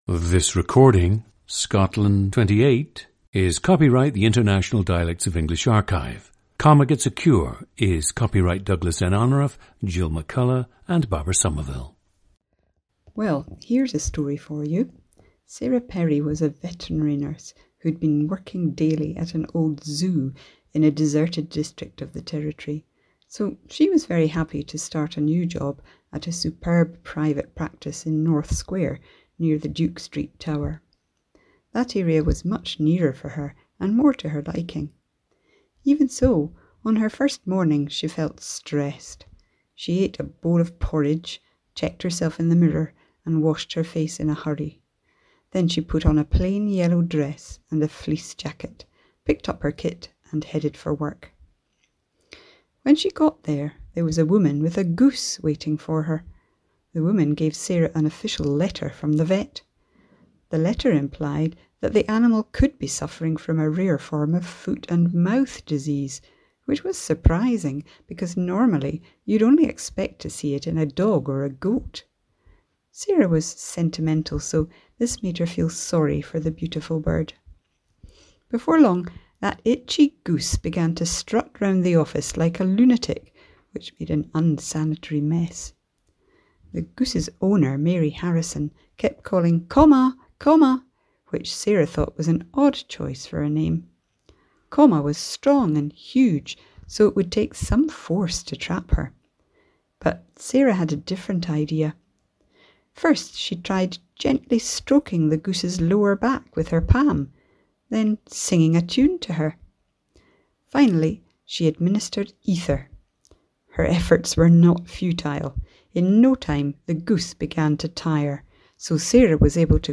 Listen to Scotland 28, a 68-year-old woman from Bathgate, West Lothian, and also from Scottish Borders and Edinburgh, Scotland.
GENDER: female
She became bilingual.
• Recordings of accent/dialect speakers from the region you select.